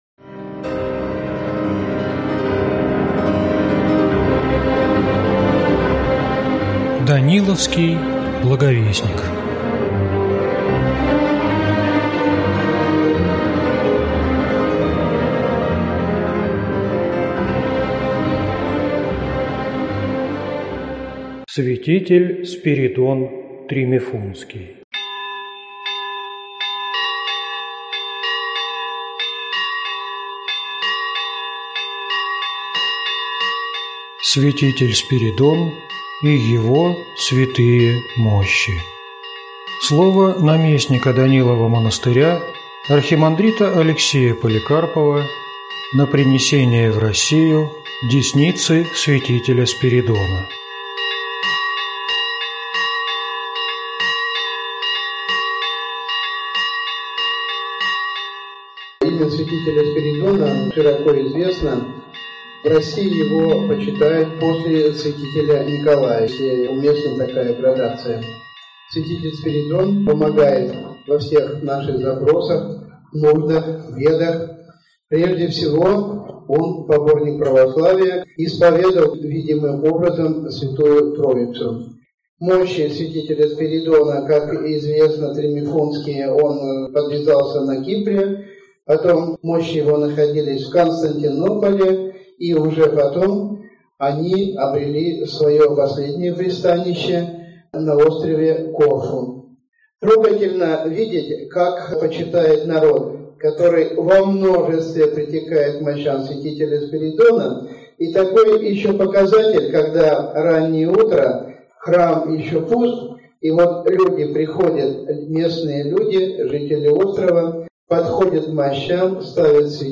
Аудиокнига Спиридон Тримифунтский святитель. Житие, чудеса, акафист | Библиотека аудиокниг